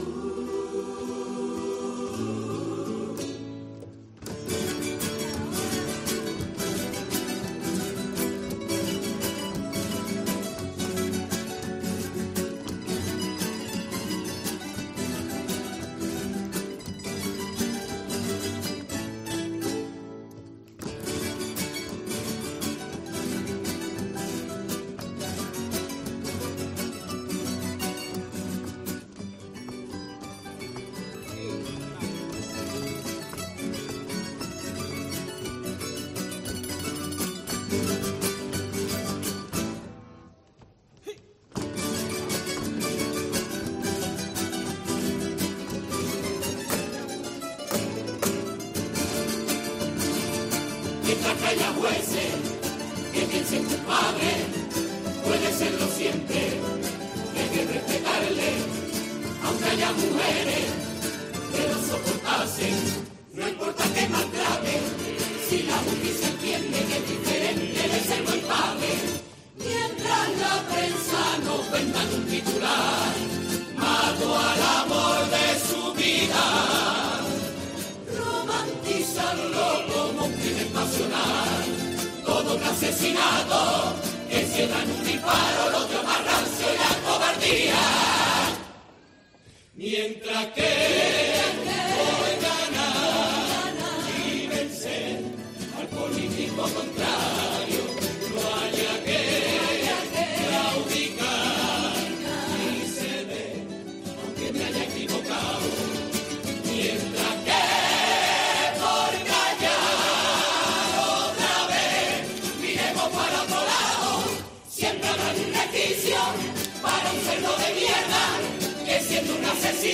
El tango a la violencia machista del coro La Voz
Carnaval